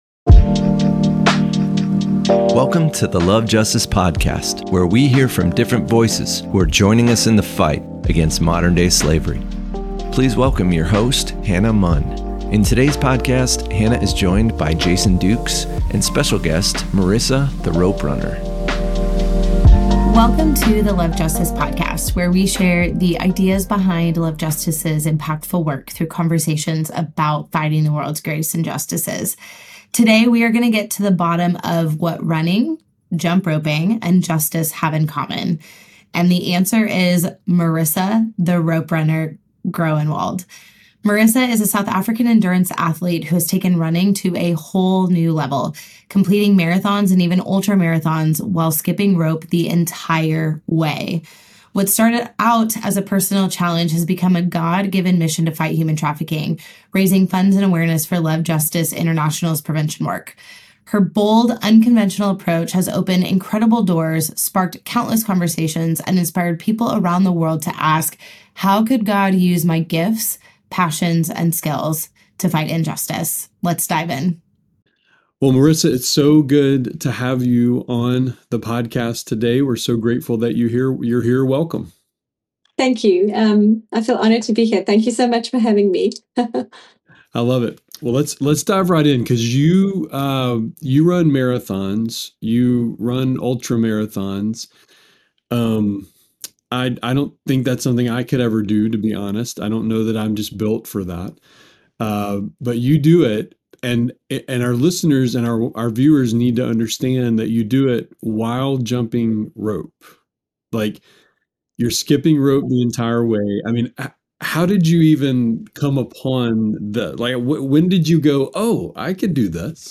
As a resource for those compelled by the love of Jesus to live justly and love mercy, the LOVE JUSTICE podcast is a collection of conversations about fighting the world's greatest injustices, like preventing human trafficking and empowering the most vulnerable among us.